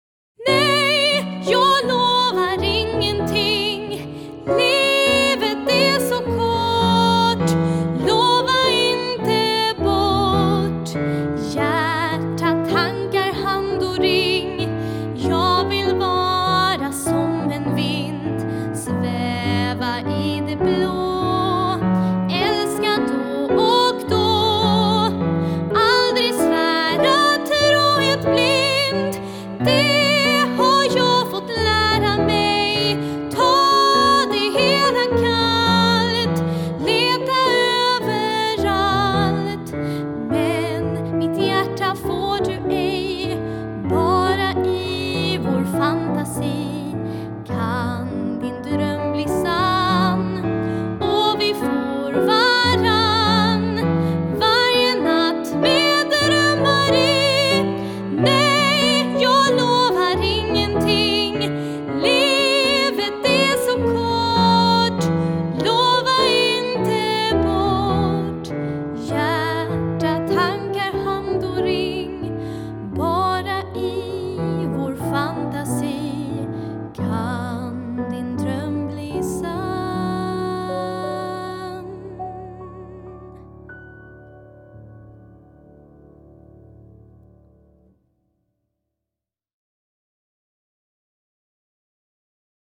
Piano och arr